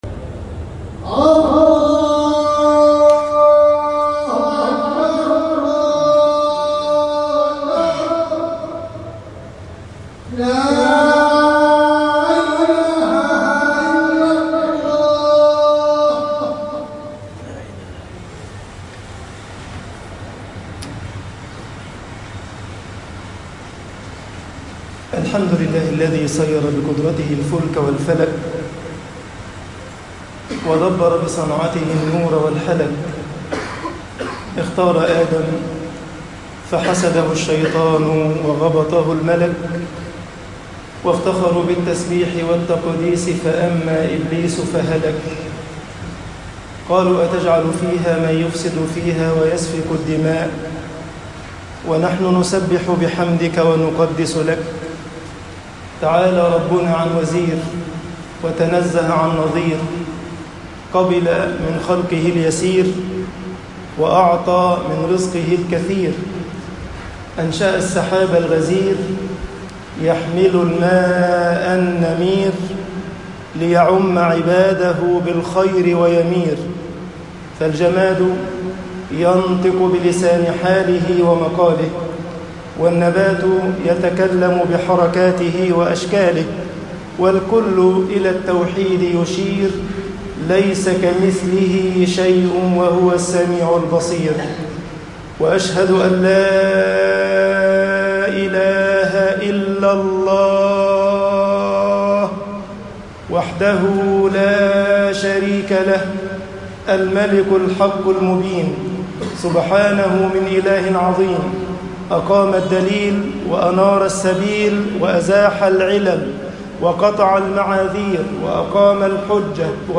خطب الجمعة - مصر احذروا تقلبات الزَّمان طباعة البريد الإلكتروني التفاصيل كتب بواسطة